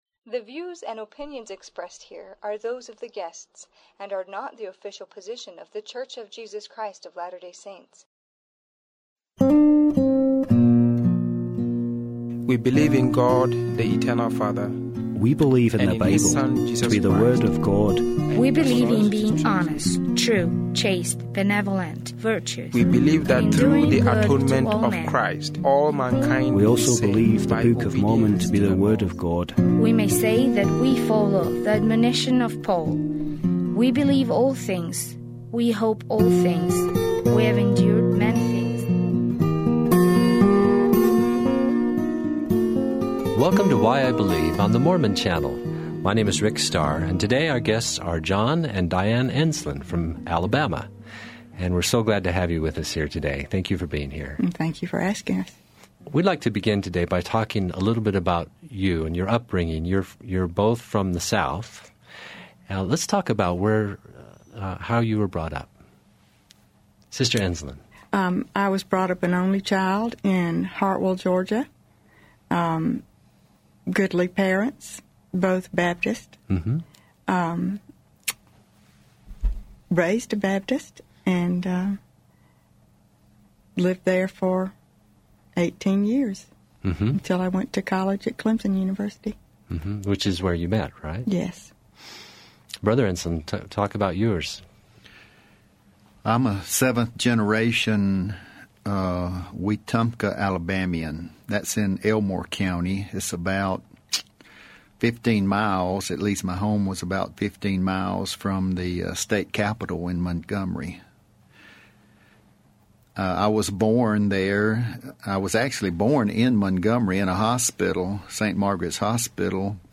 Why_I_Believe_Interview.mp3